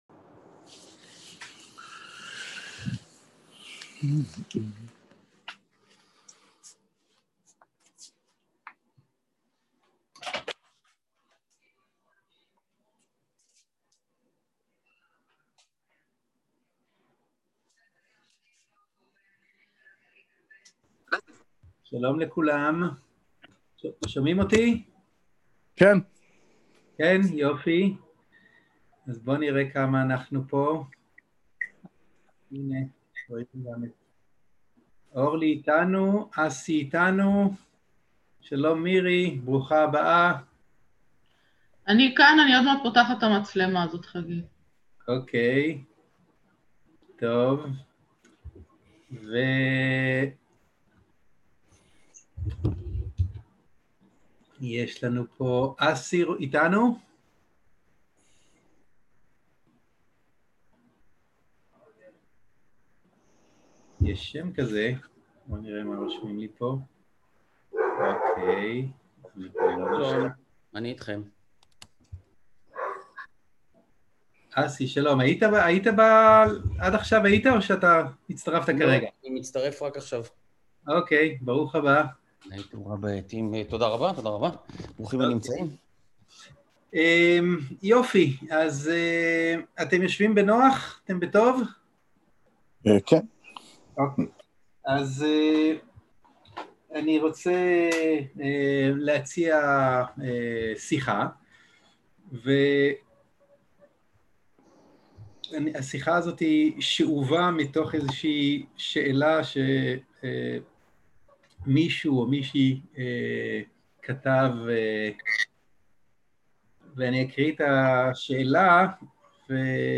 סוג ההקלטה: שיחות דהרמה
איכות ההקלטה: איכות גבוהה